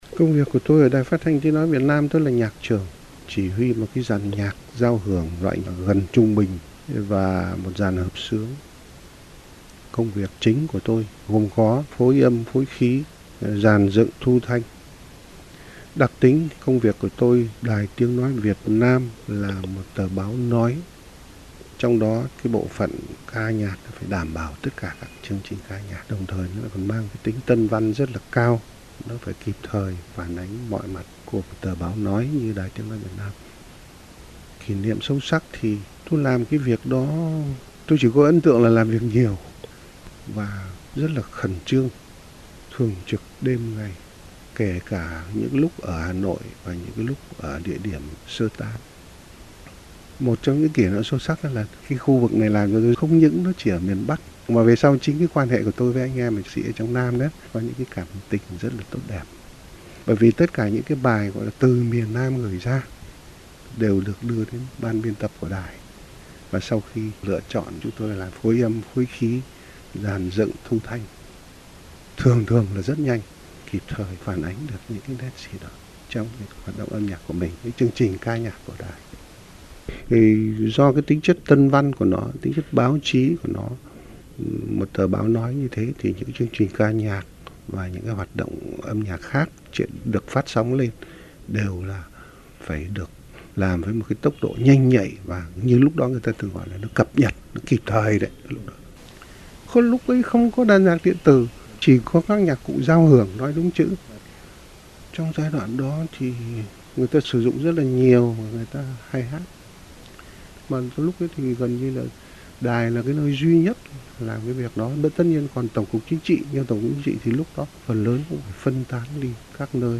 Băng tiếng phỏng vấn nhạc sĩ Hoàng Vân
Vào những năm cuối đời, Đài phát thanh tiếng nói Việt nam có tổ chức nhiều buổi trò chuyện với nhạc sĩ Hoàng Vân.
Phỏng vấn